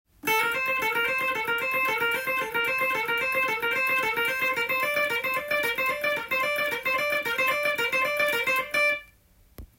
指がなまらないギターフレーズ集TAB譜
譜面通り弾いてみました